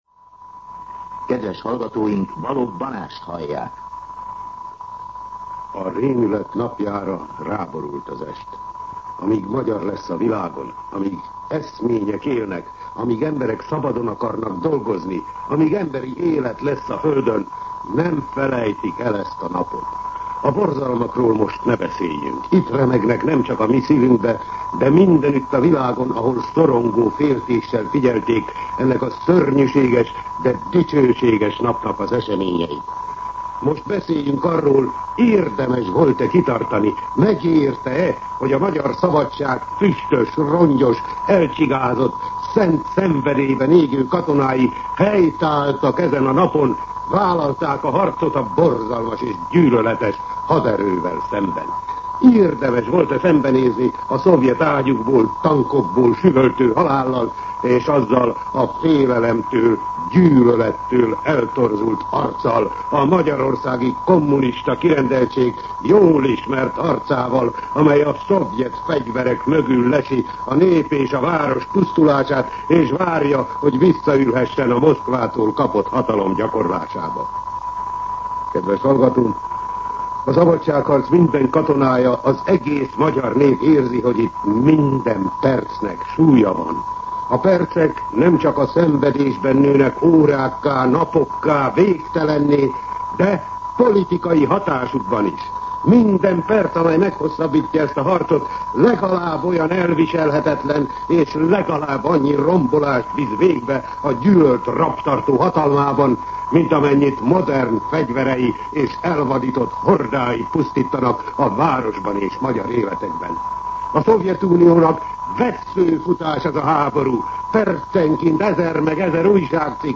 Rendkívüli kommentár
MűsorkategóriaKommentár